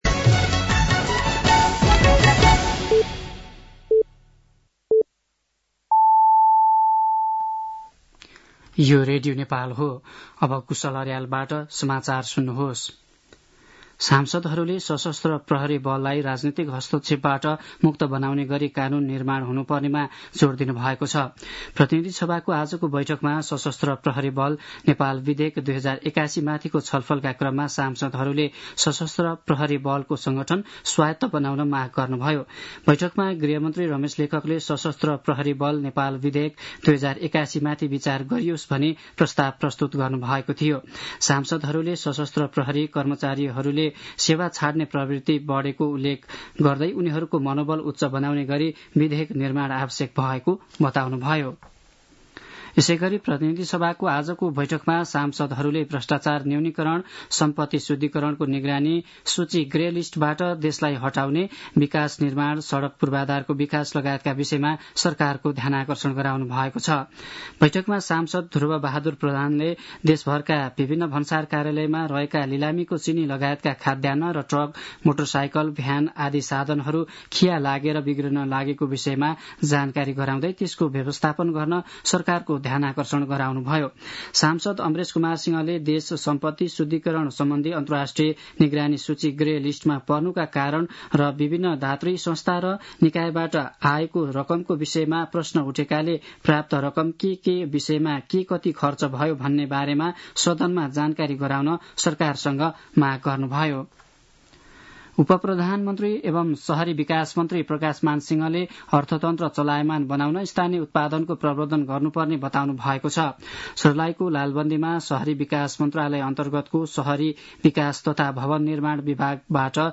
साँझ ५ बजेको नेपाली समाचार : १३ फागुन , २०८१
5-pm-nepali-news-11-12.mp3